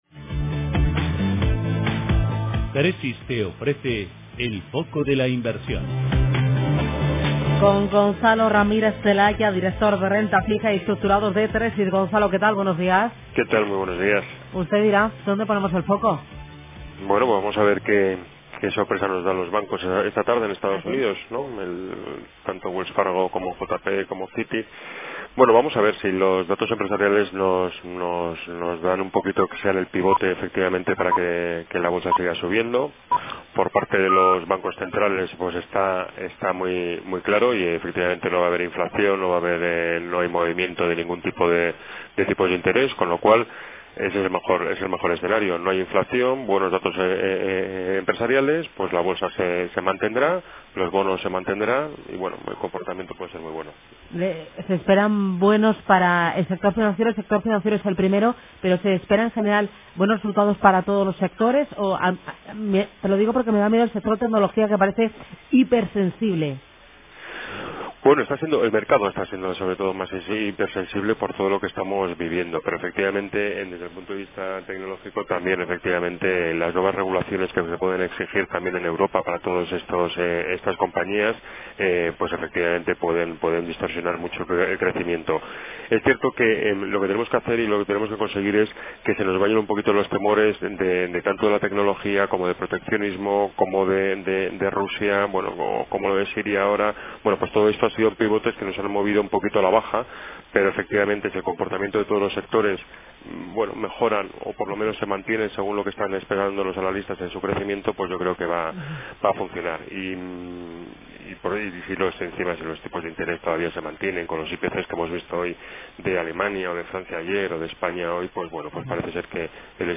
En Radio Intereconomía todas las mañanas nuestros expertos analizan la actualidad de los mercados.